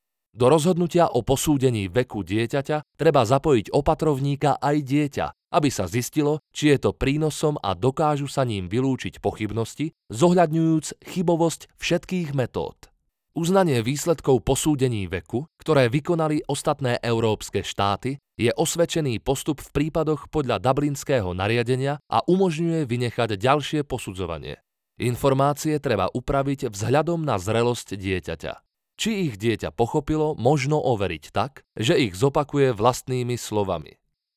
Male
E-learning
A strong voice that has a very pleasant gentle tone.
GentleStrong
All our voice actors record in their professional broadcast-quality home studios using high-end microphones.